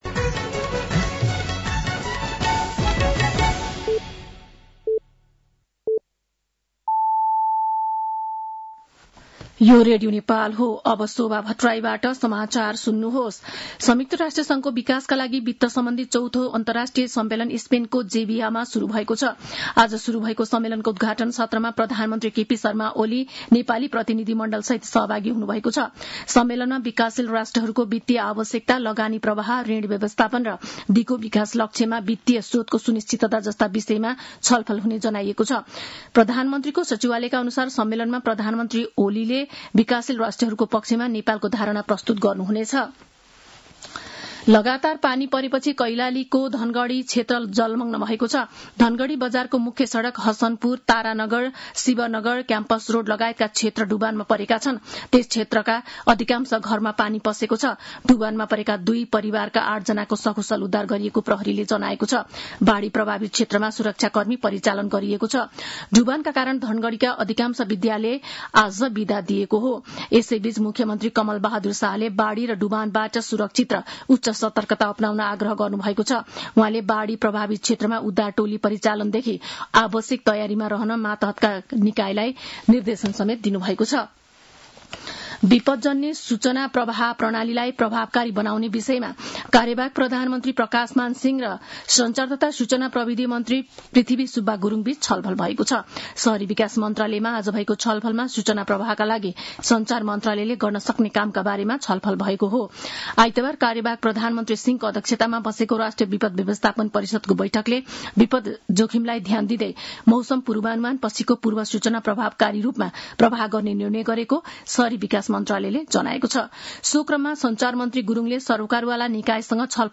साँझ ५ बजेको नेपाली समाचार : १६ असार , २०८२
5.-pm-nepali-news-1-9.mp3